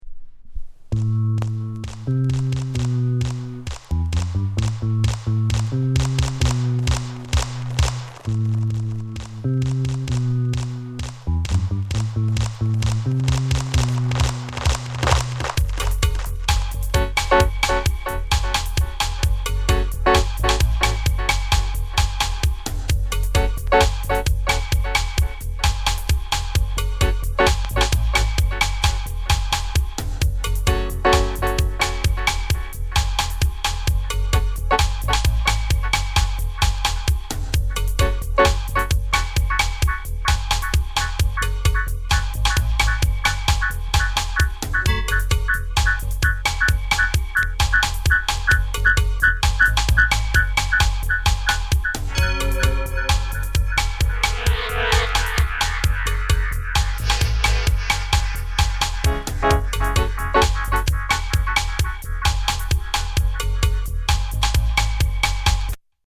KILLER STEPPER ROOTS